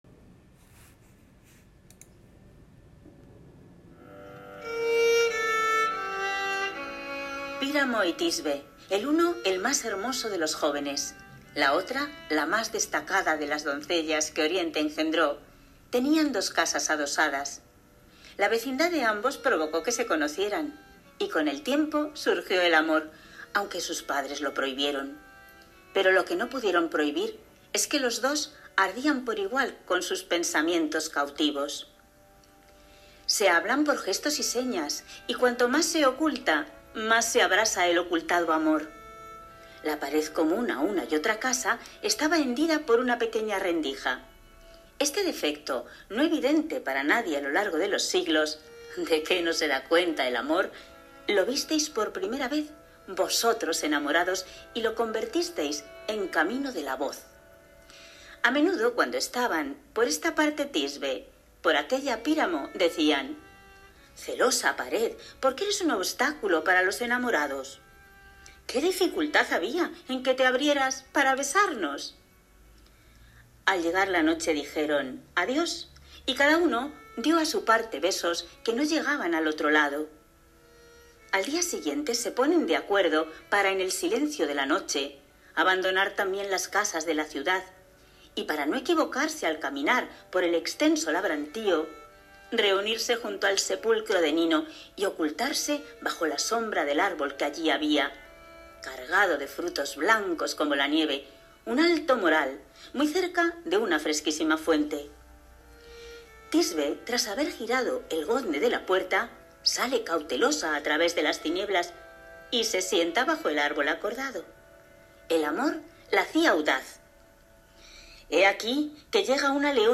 Entre sus últimas incursiones formato MP3 audio(2,35 MB) cobraba vida la trágica y legendaria historia de ‘Píramo y Tisbe’, en el encuentro online sobre ‘El amor en Grecia y Roma’ organizado por el Museo Tiflológico de la ONCE, con motivo de la festividad de San Valentín.